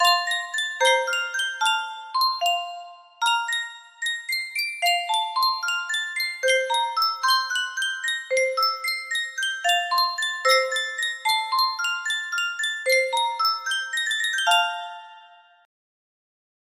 Sankyo Music Box - Mozart's Lullaby MD music box melody
Full range 60